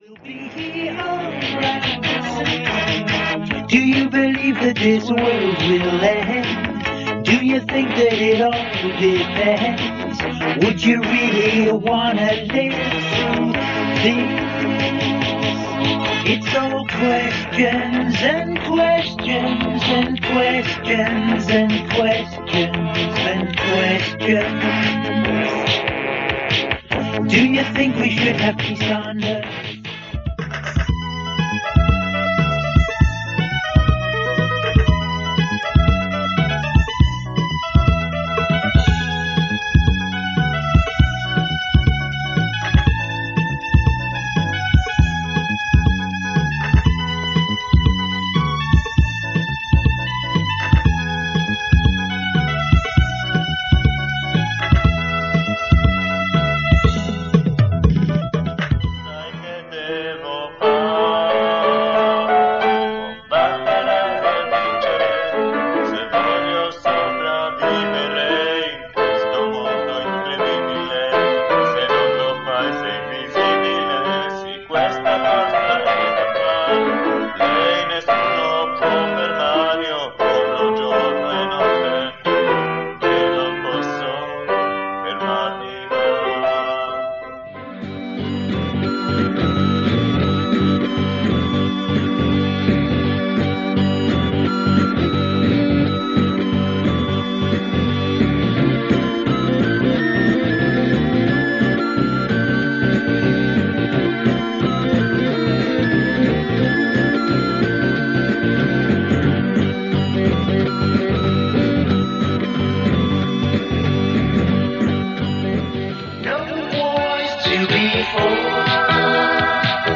The recently introduced rule allowing the use of vocals in the Home Composed Song Contest started to have a real effect, as only half of this year's songs were instrumentals.
Recap of all HCSC 1993 songs in draw order